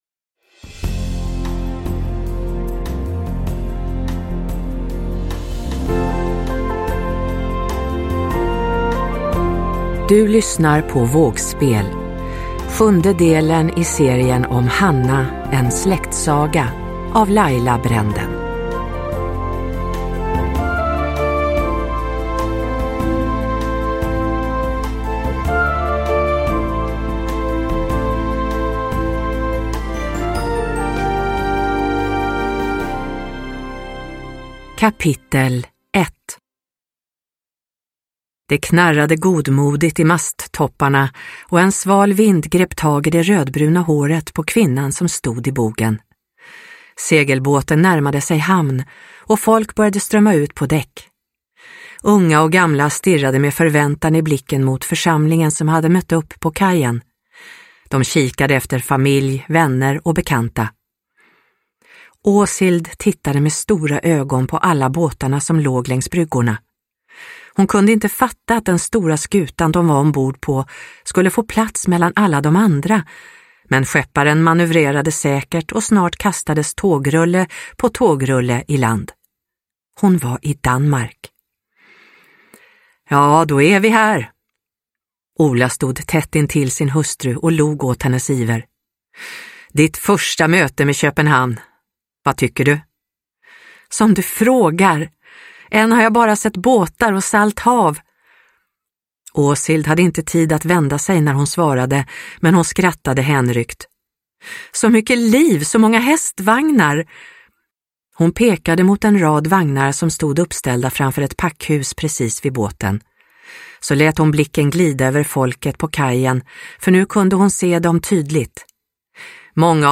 Vågspel – Ljudbok